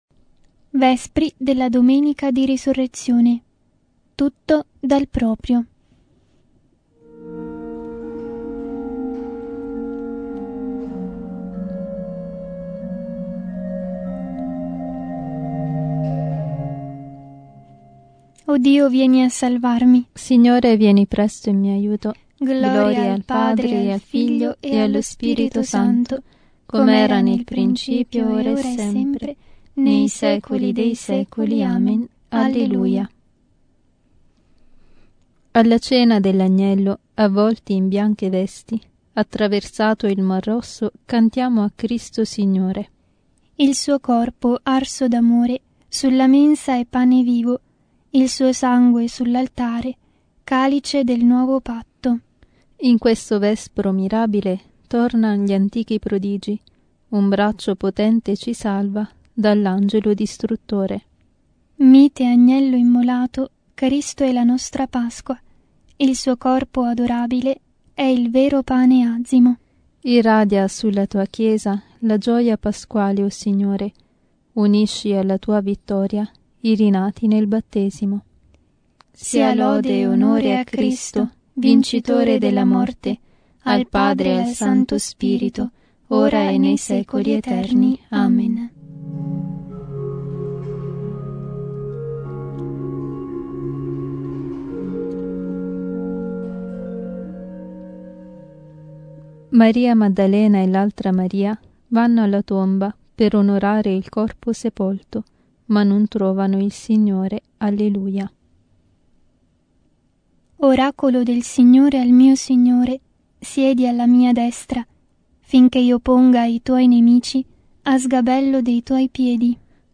Vespri – Solennità della Risurrezione di N.S.G.C